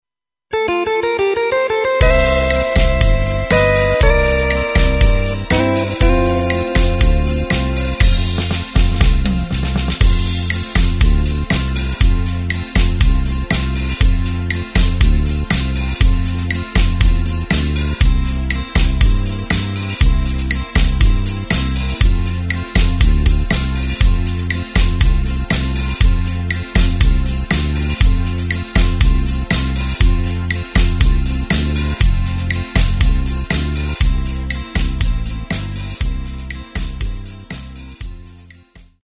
Takt:          4/4
Tempo:         120.00
Tonart:            F
Schlager aus dem Jahr 1985!